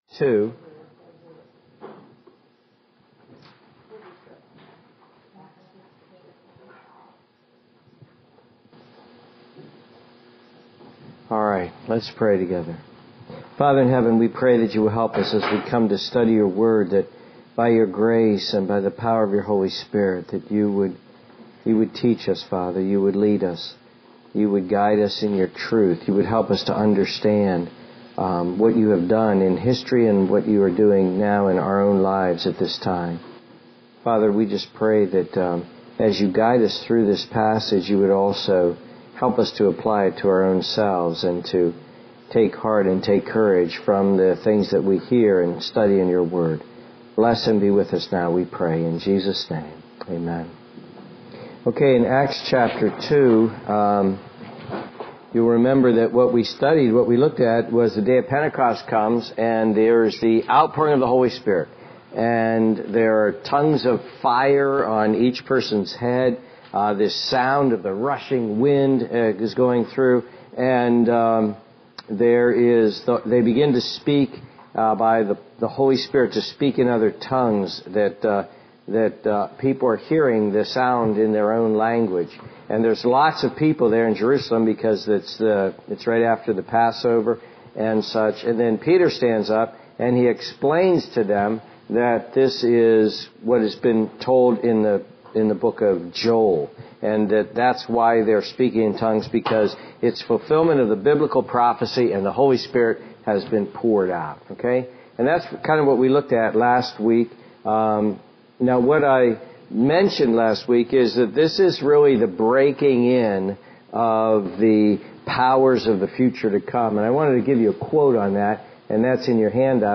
Acts: Their First Sermon — Crossroads Christian Fellowship